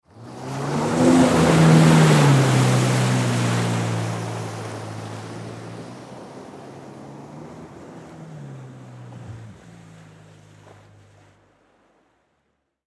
Звук гидроцикла вдалеке